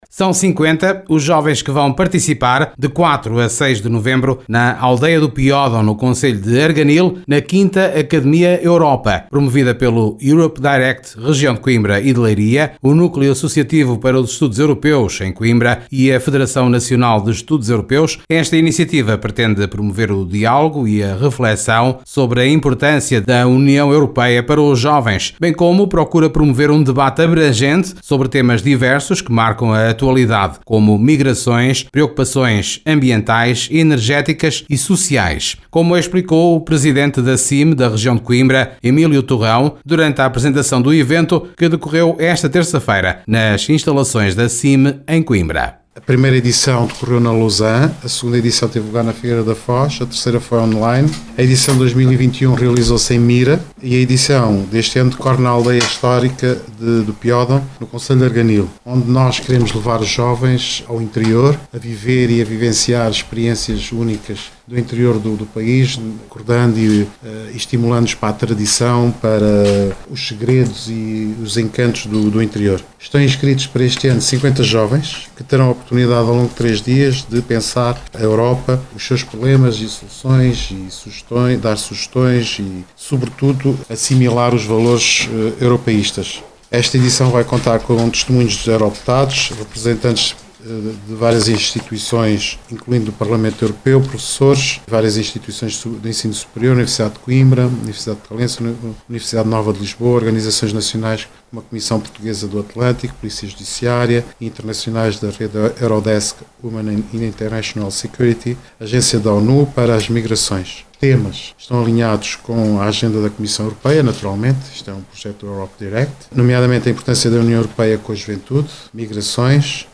Ouça aqui um excerto da apresentação feita pelo presidente da Comunidade Intermunicipal (CIM) Região de Coimbra (RC), Emílio Torrão.